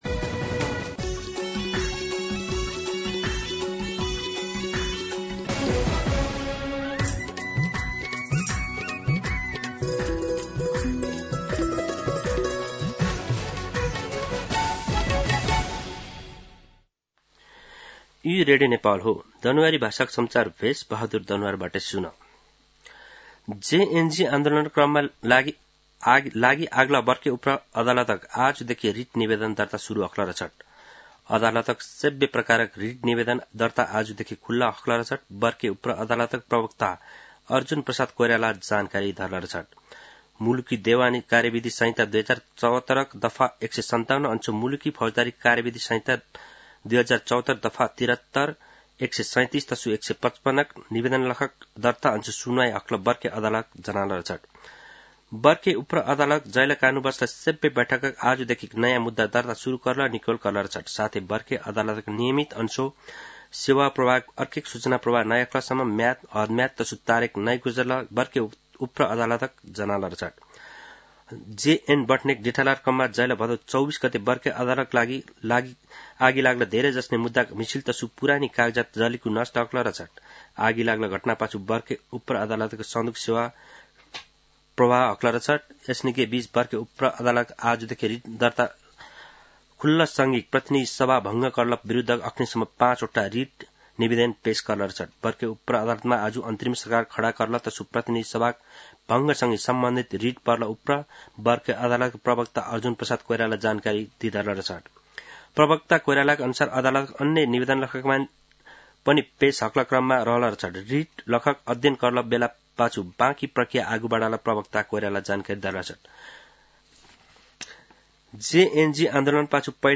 दनुवार भाषामा समाचार : २८ असोज , २०८२